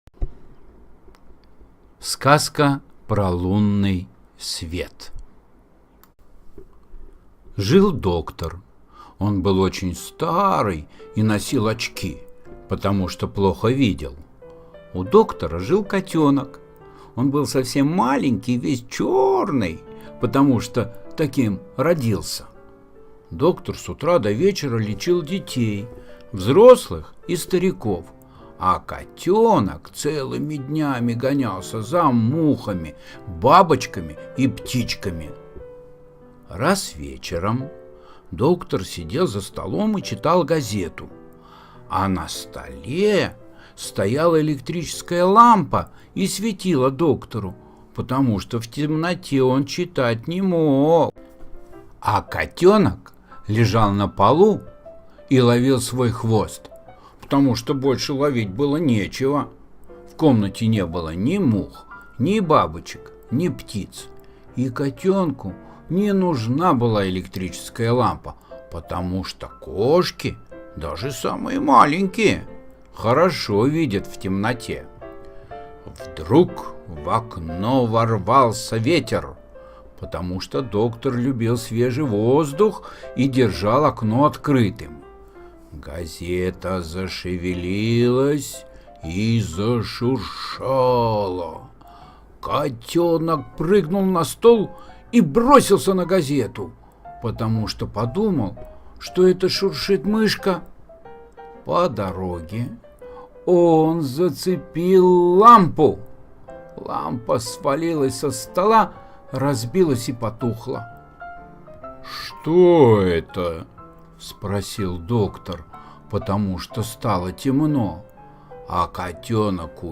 Сказка про лунный свет (аудиоверсия) – Гернет Н.В.